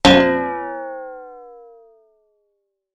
SFX综艺常用 (72)音效下载
SFX音效